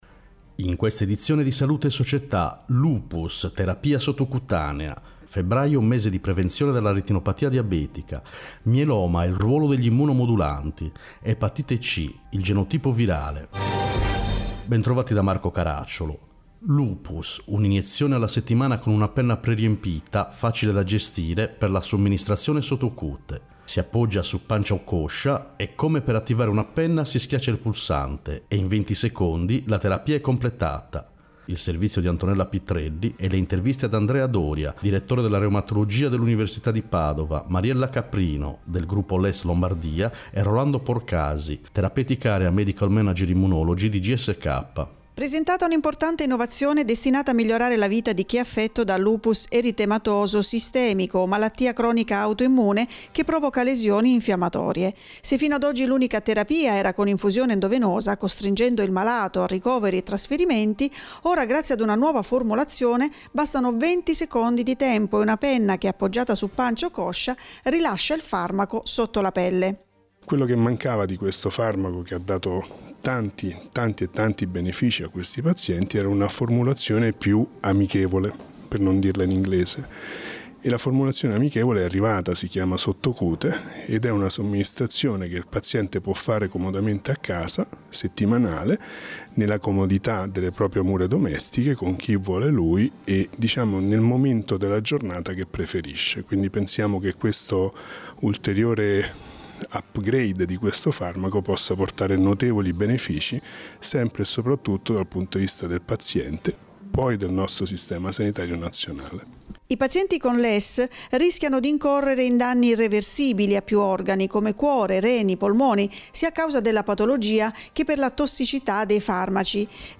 In questa edizione: 1. Lupus, Terapia sottocute 2. Retinopatia diabetica, Mese della Prevenzione 3. Mieloma, Ruolo degli immunomodulanti 4. Epatite C, Genotipo virale Interviste